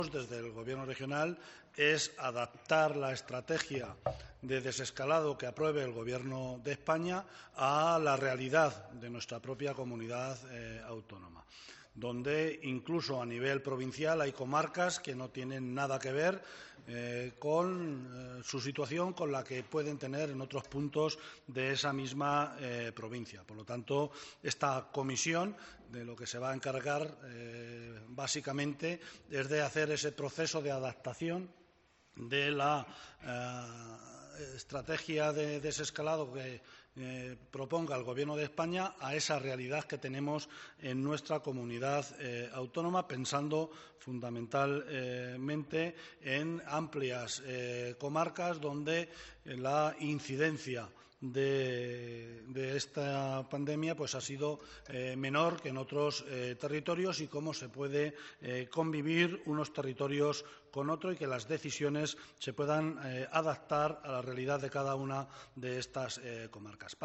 Tras una videoconferencia con los delegados de la Junta en cada una de las cinco provincias, Guijarro ha explicado en rueda de prensa que se tendrá en cuenta la opinión de los representantes del Gobierno autonómico en estos territorios, ya que «tienen un conocimiento más cercano» de las comarcas de la región para adaptar así la estrategia de desescalada «a la realidad» de la Comunidad Autónoma, toda vez que incluso a nivel provincial hay comarcas con situaciones muy distintas.